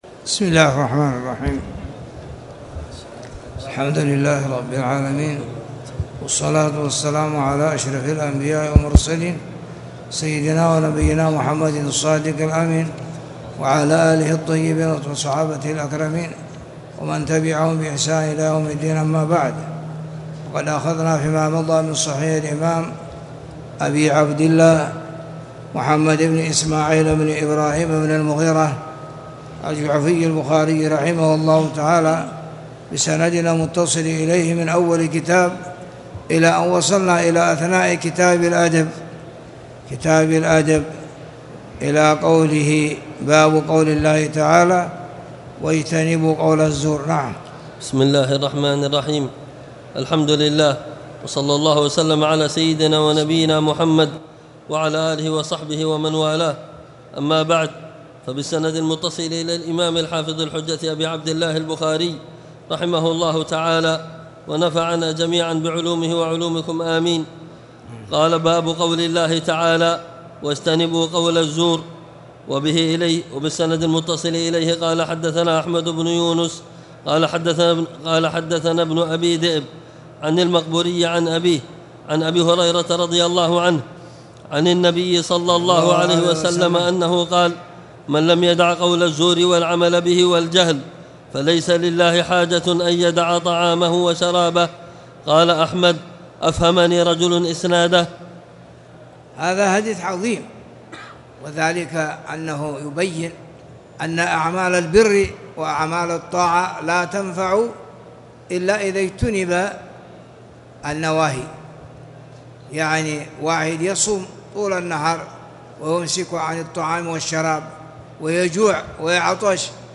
تاريخ النشر ٦ جمادى الآخرة ١٤٣٨ هـ المكان: المسجد الحرام الشيخ